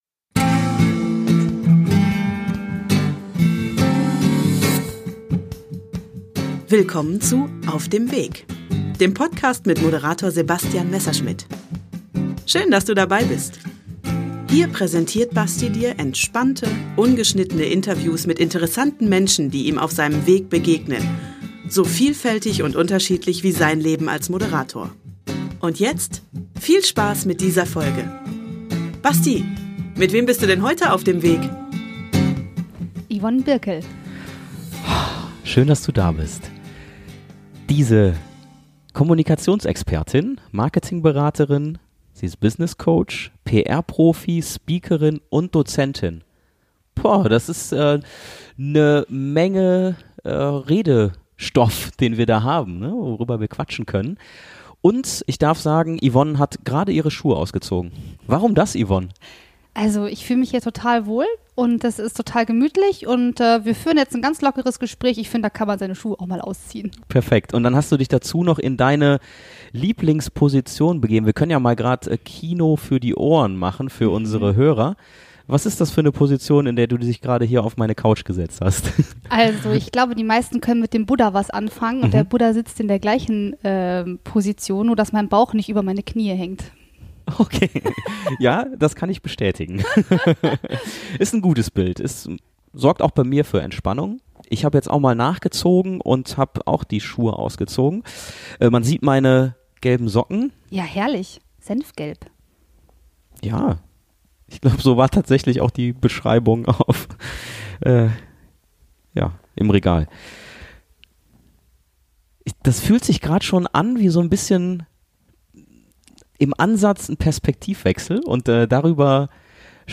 Darüber sprechen wir, beide befreit von unseren Schuhen, auf meiner Couch.